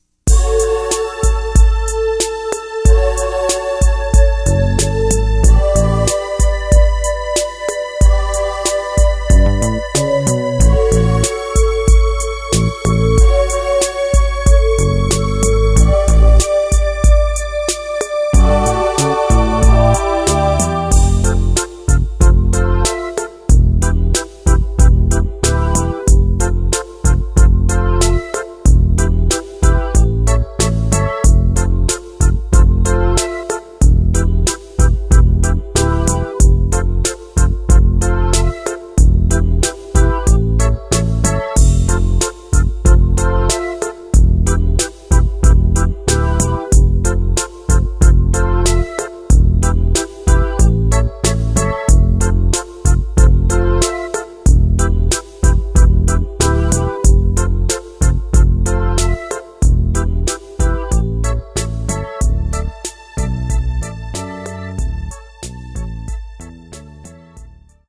Key of C